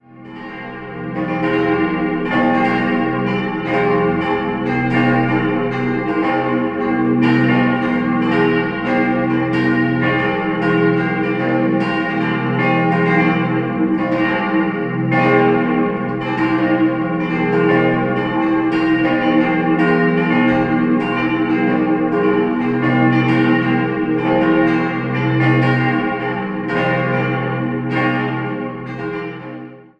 Jahrhundert wurde eine neue Kirche errichtet und diese in den Jahren 1934 bis 1936 erweitert. 4-stimmiges Geläute: c'-f'-g'-a' Die Glocken wurden im Jahr 1948 von der Gießerei Junker in Brilon gegossen.